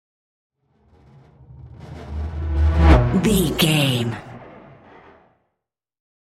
Whoosh electronic fast
Sound Effects
Fast
futuristic
high tech
intense